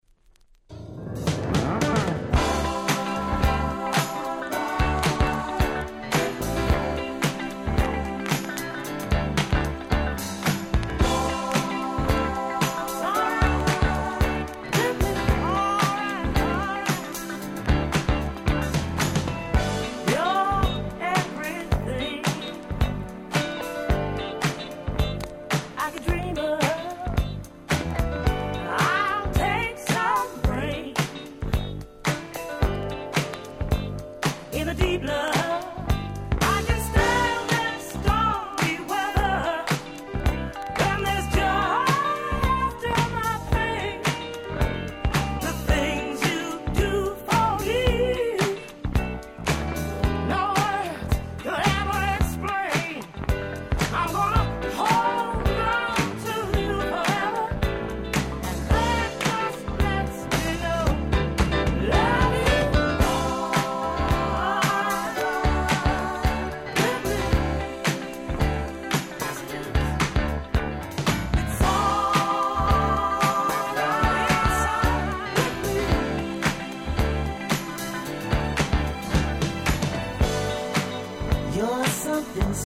82' 鉄板Dance Classics !!